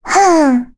Jane-Vox_Attack5.wav